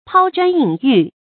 pāo zhuān yǐn yù
抛砖引玉发音